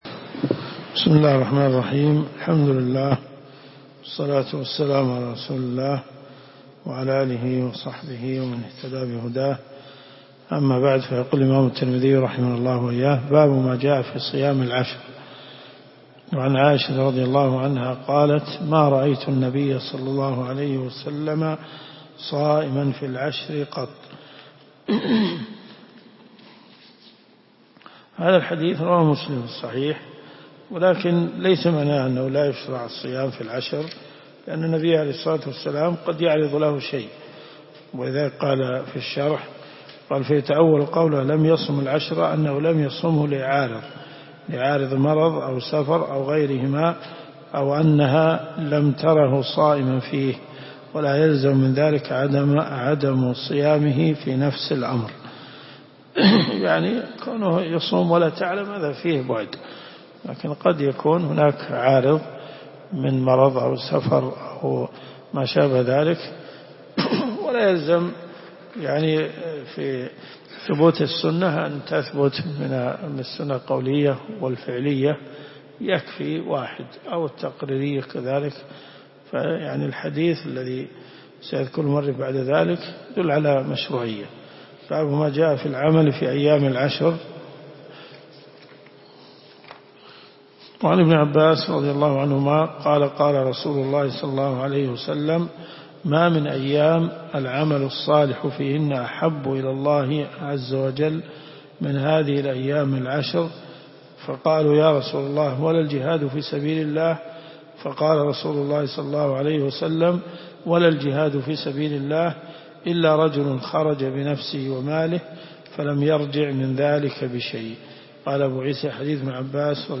الرئيسية الكتب المسموعة [ قسم الحديث ] > جامع الترمذي .